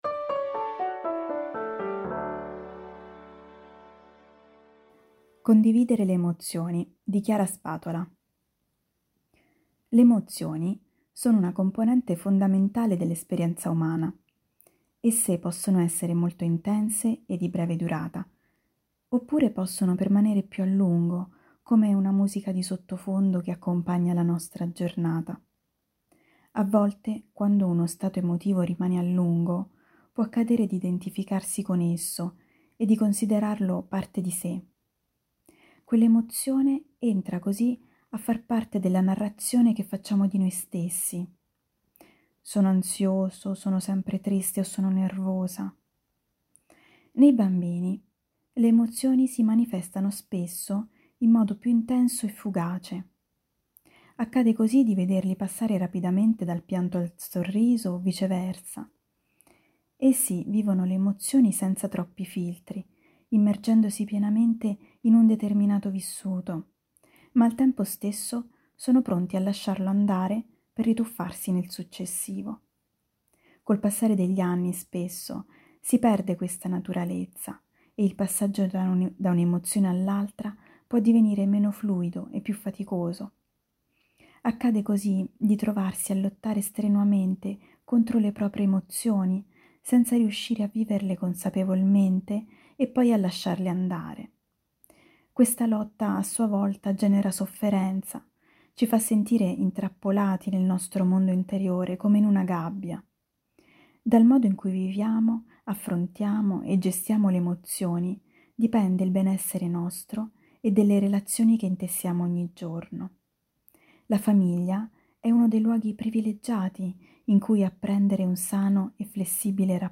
Al microfono, i nostri redattori e i nostri collaboratori.
Ecco i 10 articoli letti per voi dalla rivista di novembre.